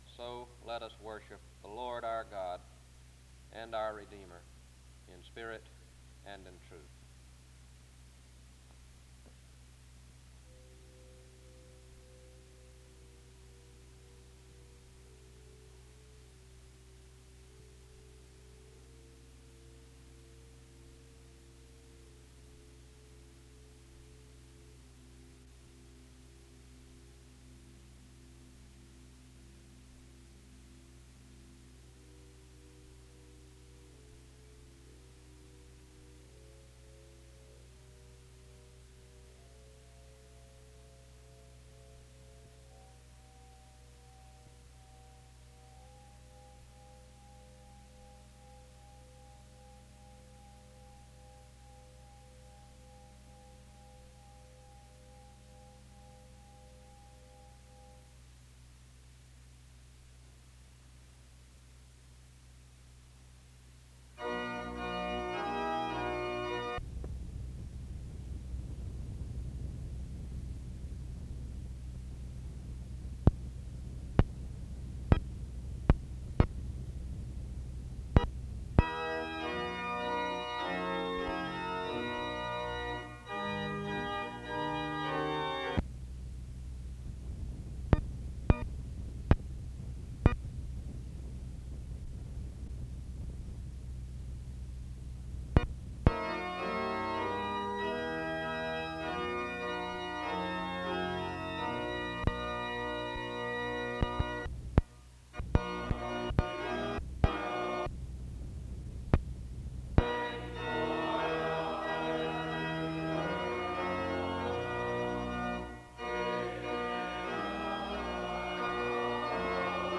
The service begins with music from 0:00-5:05.
There is a prayer from 5:20-6:01. The speaker is introduced from 6:05-7:05.
SEBTS Chapel and Special Event Recordings SEBTS Chapel and Special Event Recordings